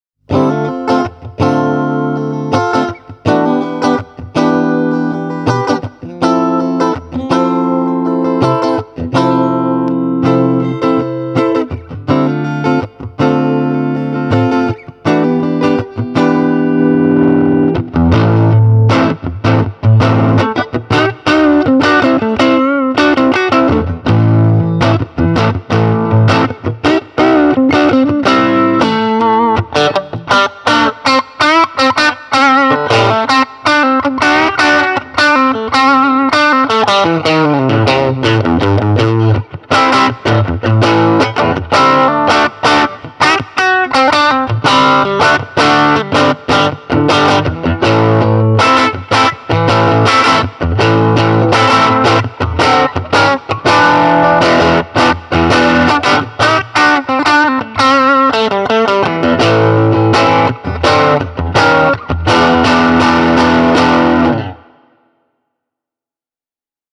Soundiltaan Tokai TST-50 Modern on aito laatu-Strato isolla L:llä.
Tässä Tokai TST-50 Modern on kytketty käsinjuotettuun Tweed Champ -klooniin (Juketone True Blood):
tokai-tst-50-modern-juketone-true-blood.mp3